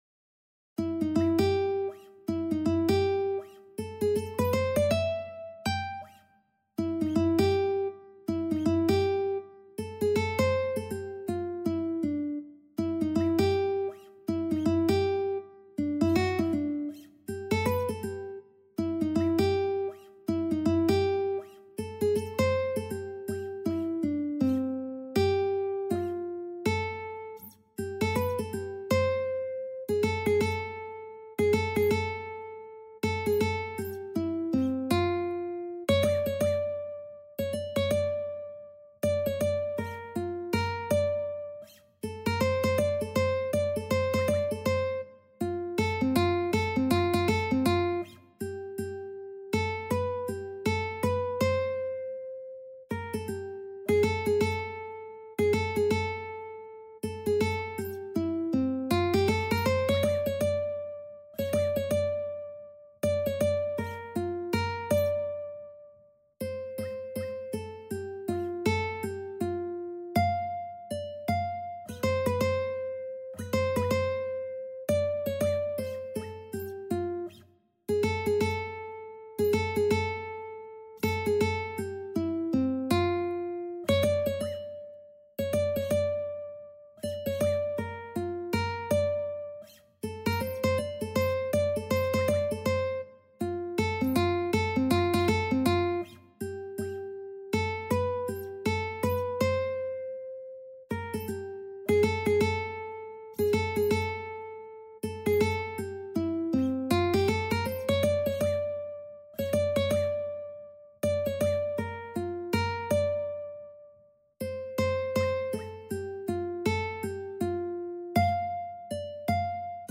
4/4 (View more 4/4 Music)
Ukulele  (View more Intermediate Ukulele Music)
Jazz (View more Jazz Ukulele Music)